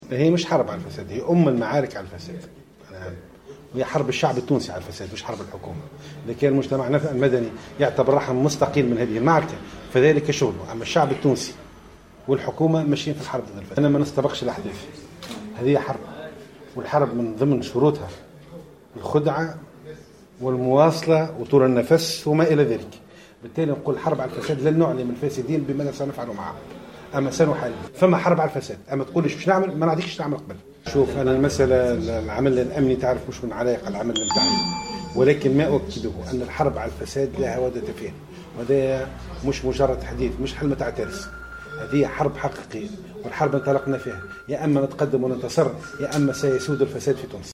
وصف مبروك كورشيد، كاتب الدّولة لدى وزارة المالية المكلف بأملاك الدّولة والشؤون العقارية، في تصريح للجوهرة أف أم، اليوم الجمعة، الحرب التي تُشن على الفساد، بـ"أم المعارك" التي تخوضها الحكومة إلى جانب الشعب، رافضا الكشف عن معطيات إضافية بخصوص إيقافات أخرى محتملة قد تطال رجال أعمال آخرين لأن الحرب "تحتاج للخداع وطول النفس" حسب تعبيره.